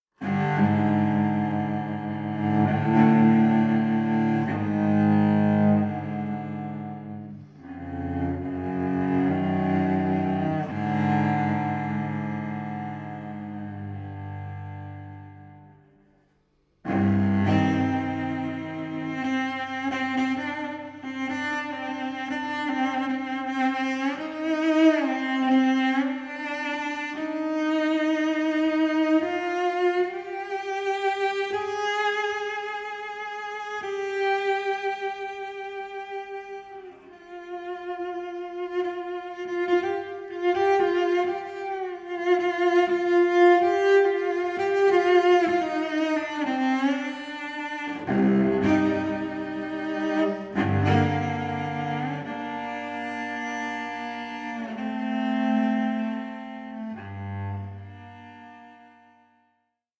cellist
solo work